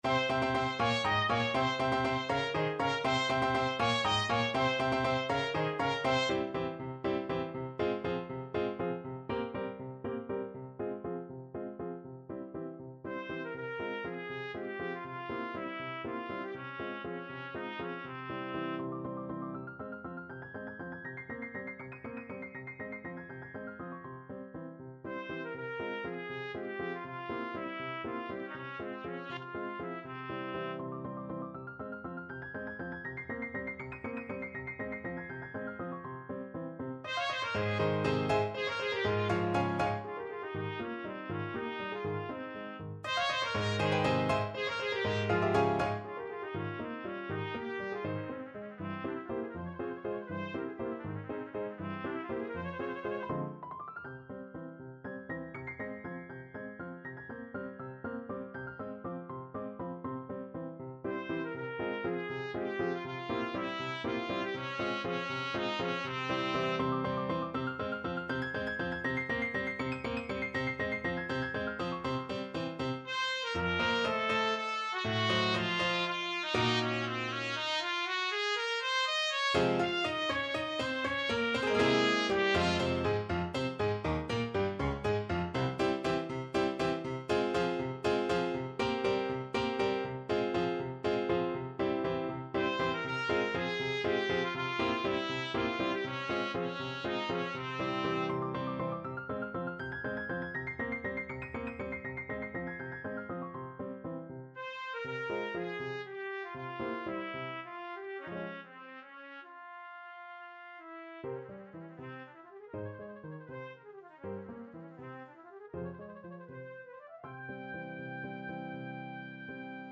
Trumpet
3/8 (View more 3/8 Music)
B4-G6
F minor (Sounding Pitch) G minor (Trumpet in Bb) (View more F minor Music for Trumpet )
Allegro vivo (.=80) (View more music marked Allegro)
Classical (View more Classical Trumpet Music)
bizet_aragonaise_TPT.mp3